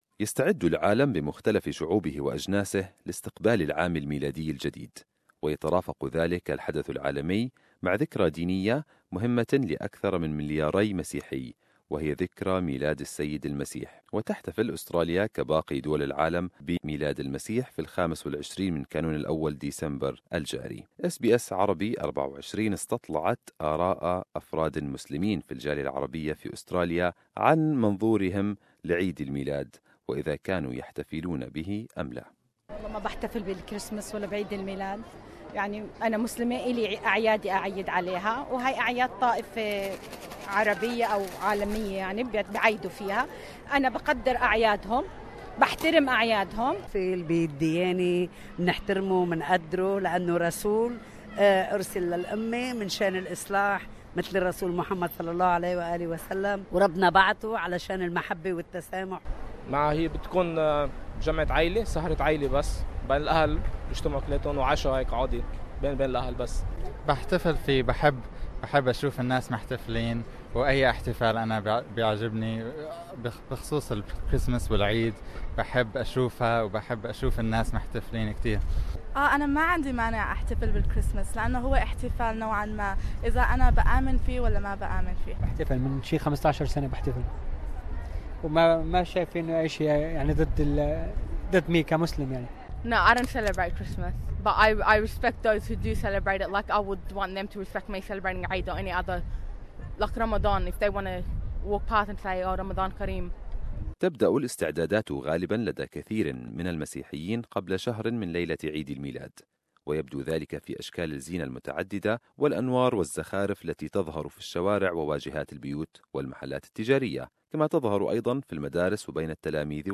SBS Arabic24 took to the streets of Sydney to canvass the opinions of several Muslim members of the Arab community in the central business district.